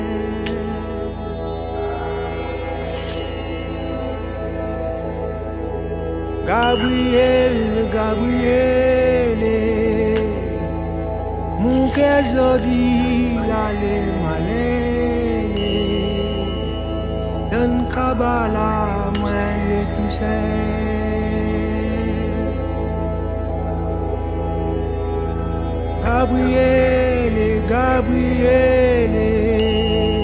électro maloya